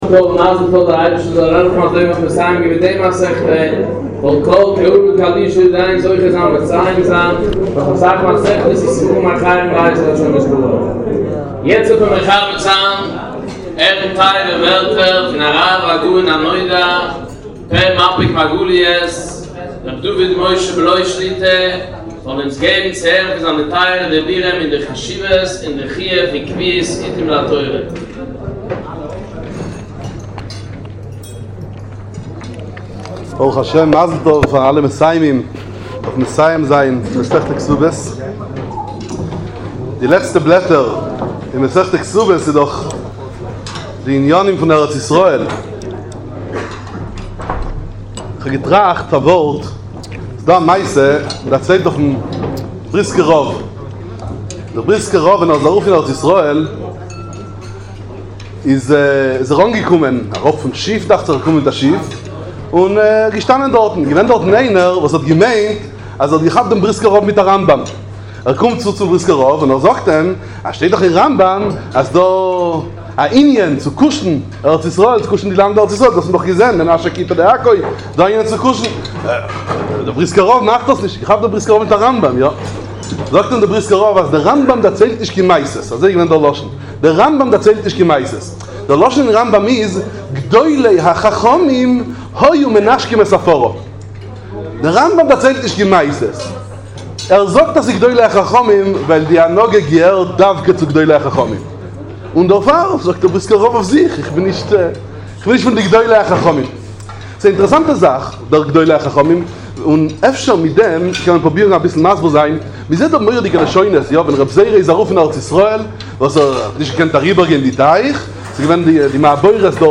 דרשת חיזוק סיום מסכת כתובות סאטמער בית שמש